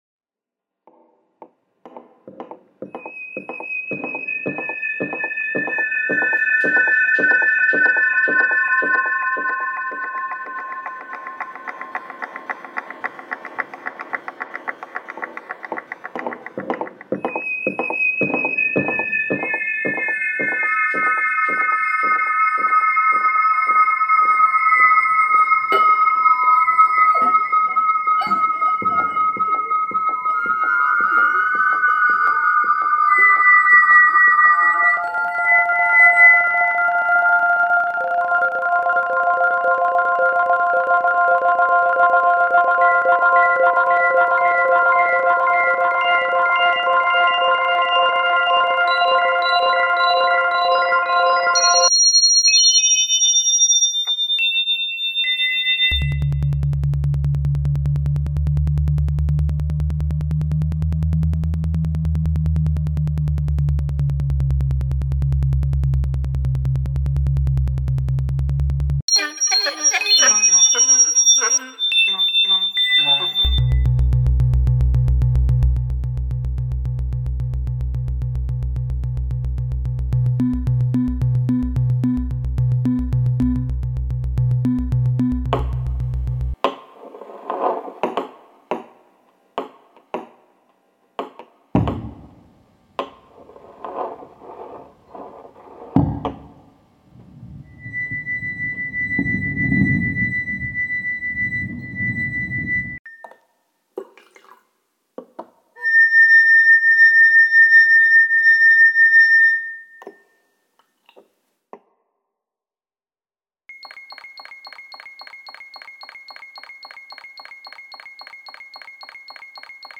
‘Half Full/Half Empty’  (GB 2016) ::: is a sound work made with manipulated recordings of circular friction on wine glass rims, the movement and placement of the glasses and the pouring and transfer of water between vessels.